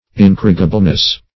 Incorrigibleness \In*cor"ri*gi*ble*ness\, n. Incorrigibility.